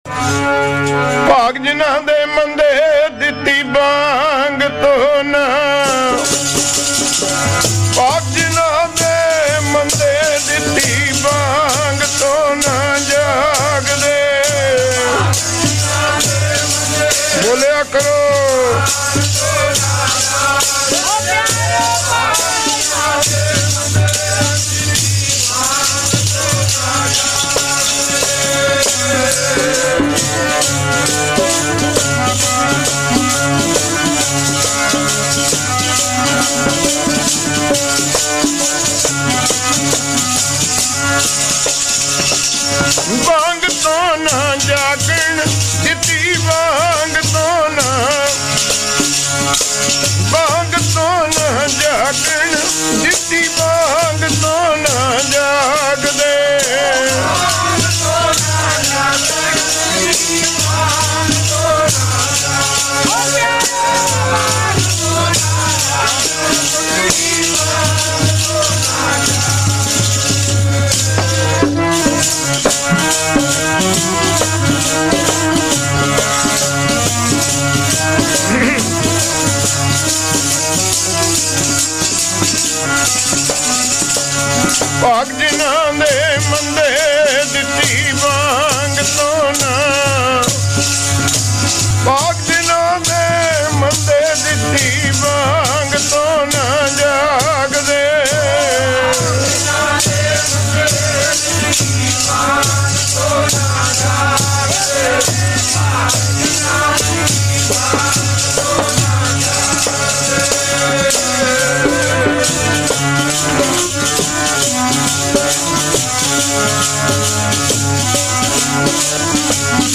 Kirtan with katha
Gurmat Vichar